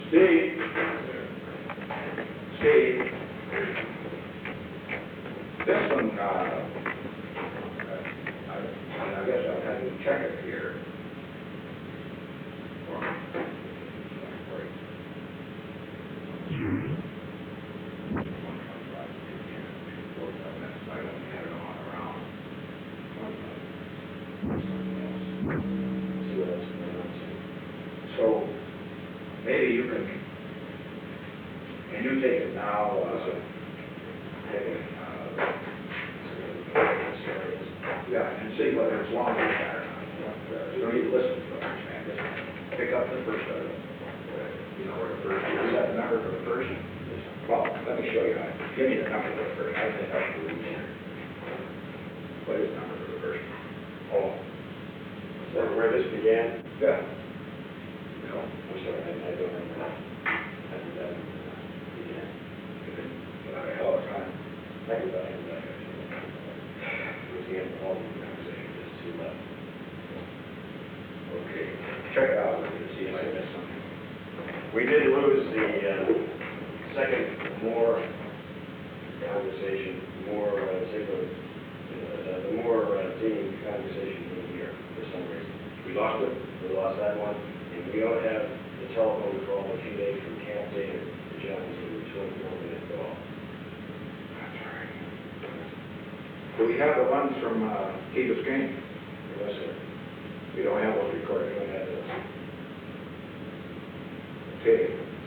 Secret White House Tapes
Conversation No. 442-48
Location: Executive Office Building